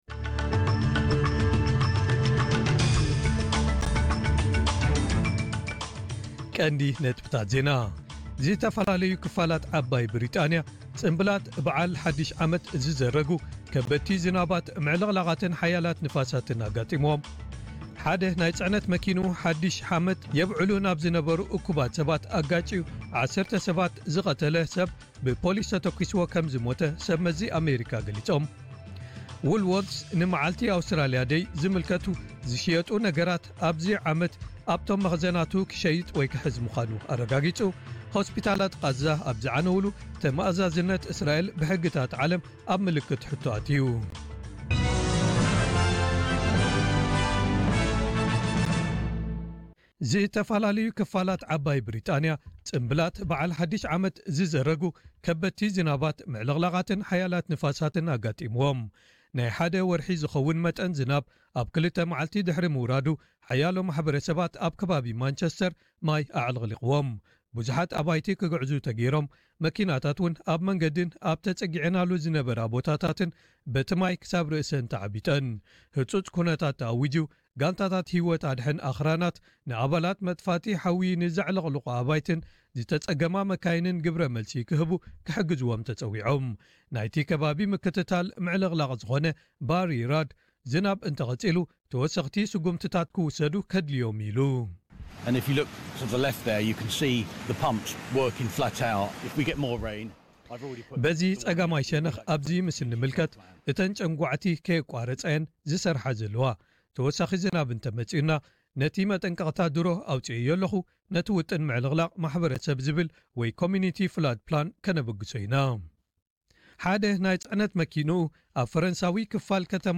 ዕለታዊ ዜና ኤስቢኤስ ትግርኛ (2 ጥሪ 2025)